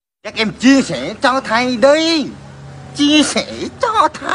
Thể loại: Câu nói Viral Việt Nam